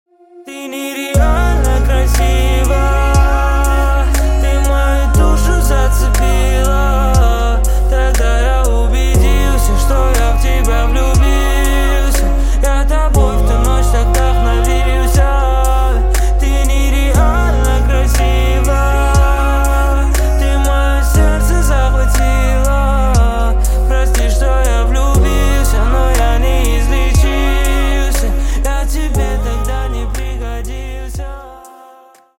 Спокойные И Тихие Рингтоны
Поп Рингтоны